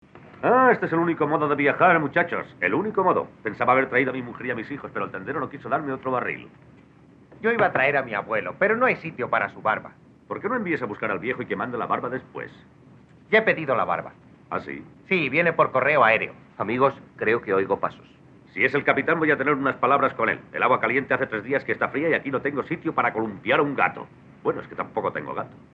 SONIDO (VERSIÓN DOBLADA)
En castellano, tenemos también una pista sonora en Dolby Digital 2.0 monoaural.
Es una pista correcta, prácticamente exenta de ruido de fondo, con un volumen uniforme y que no distorsiona. Por supuesto, evidencia también las limitaciones del sonido original, sobre todo en lo que atañe a la calidad de la reproducción músical.
Afortunadamente en este caso, el doblaje es bueno y bastante fiel al texto original inglés, sin adaptaciones localistas de las bromas.
este enlace una muestra de audio del film, para poder comparar las voces con las de otras películas de los Marx en zonadvd.